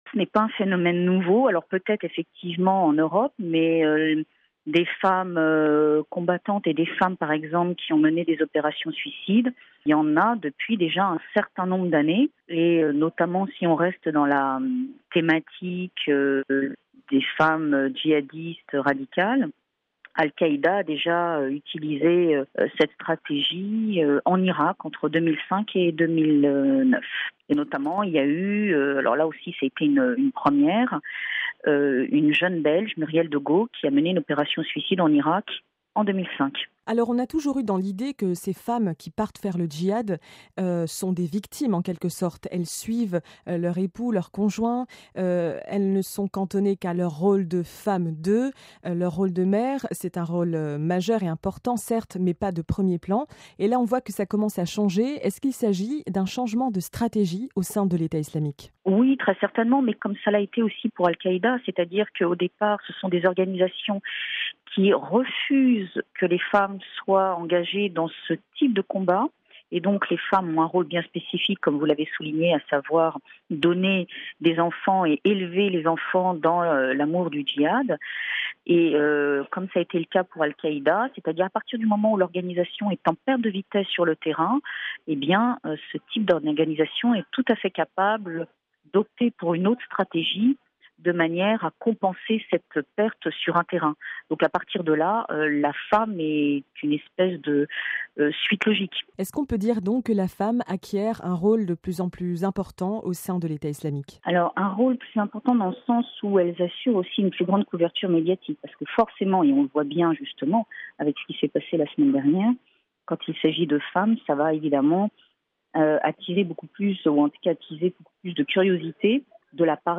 (RV) Entretien - La menace terroriste est toujours à son niveau maximal en France.